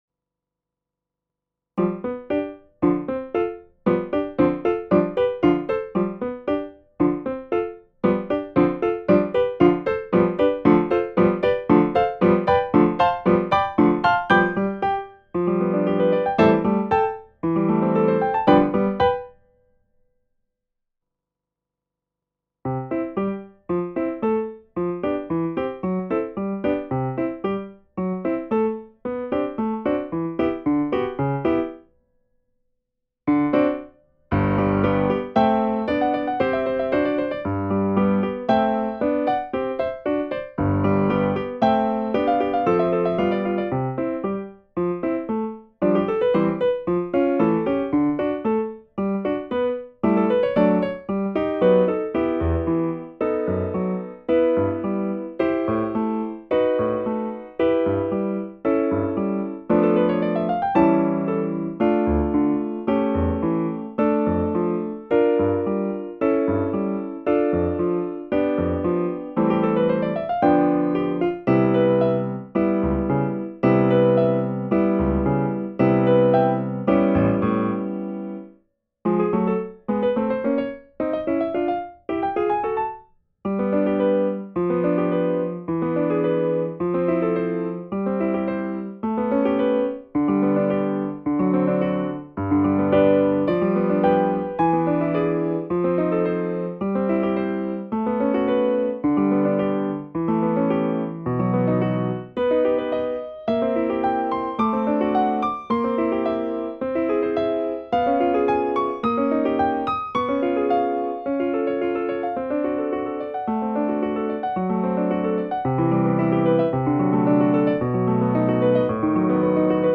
Includes access to audio of the piano accompaniment.